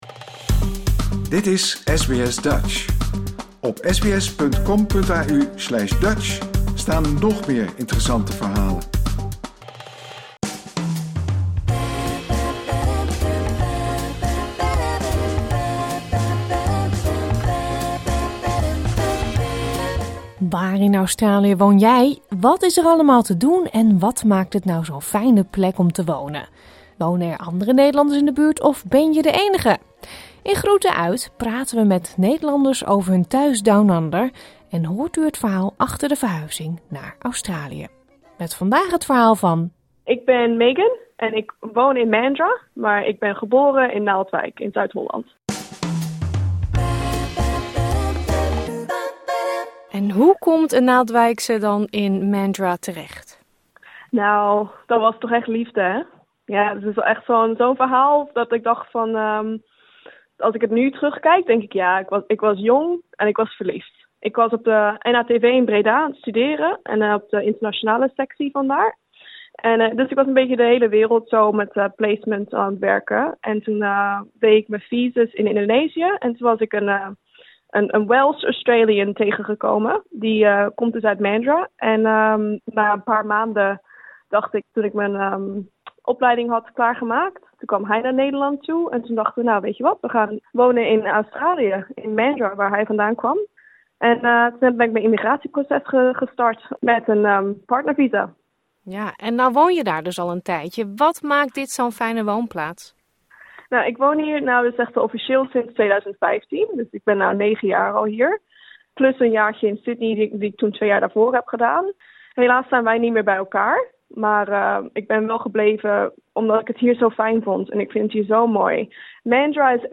In onze serie Groeten uit... vertellen Nederlanders over hun woonplaats Down Under; wat is er allemaal te doen en waarom voelen ze zich hier helemaal thuis? Ook hoor je het verhaal achter hun verhuizing naar Australië.